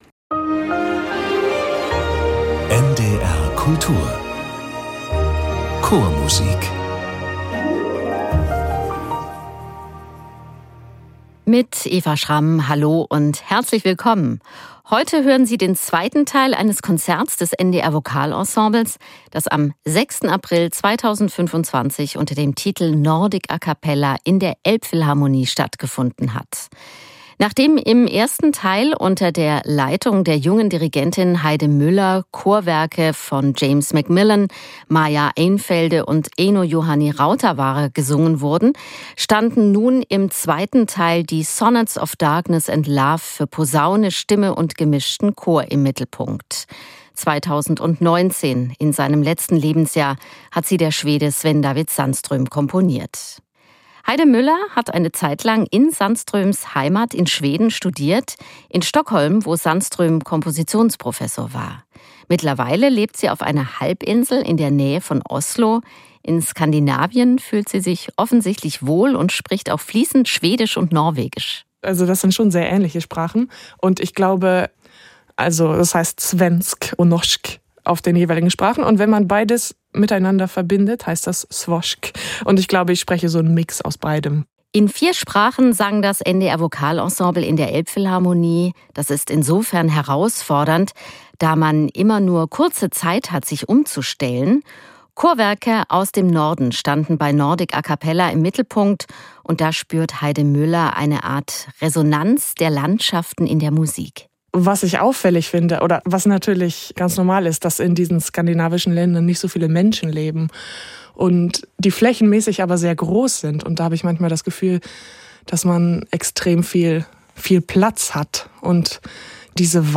mit Chormusik aus Nordeuropa und dem Baltikum.